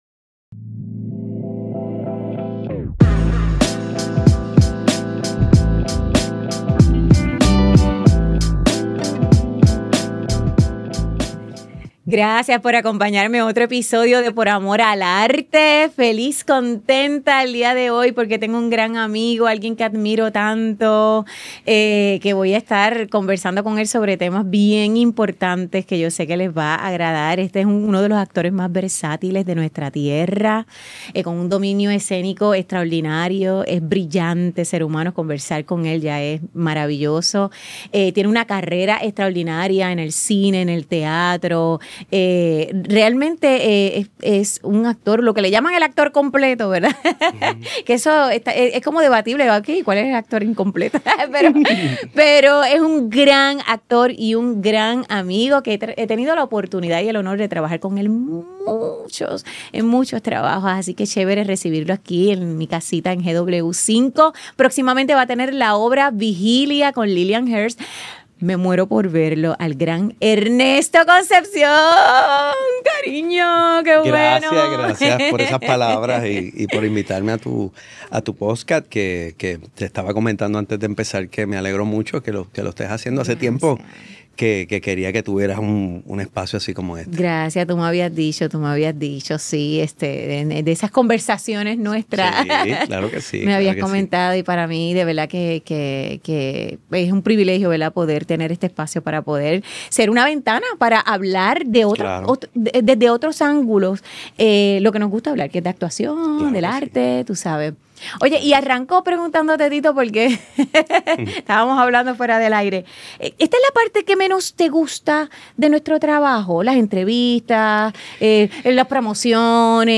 Grabado en GW-Cinco Studio para GW5 Network